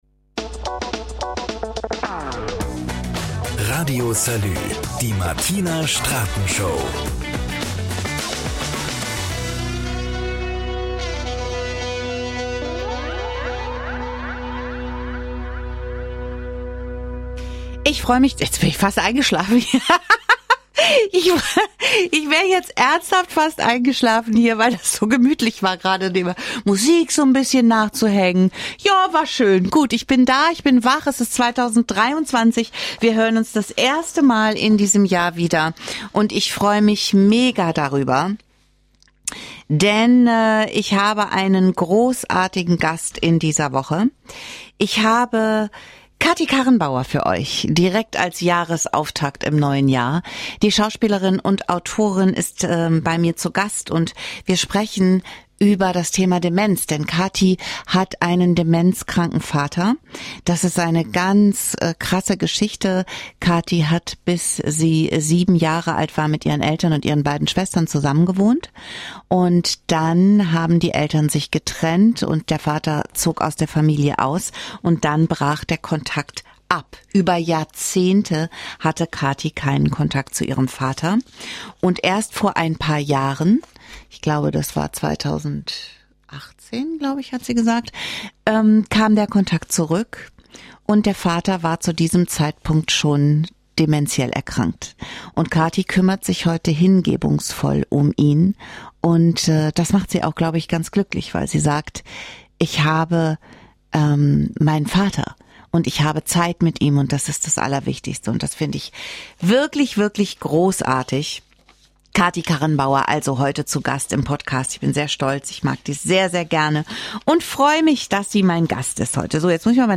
Ein ehrliches Gespräch mit der Schauspielerin und Autorin Katy Karrenbauer über eine Krankheit, die viele Familien betrifft
ist Tatort "Du bleibst hier" Gast in dieser Woche (ab 16:24) ist die Schauspielerin und Autorin Katy Karrenbauer.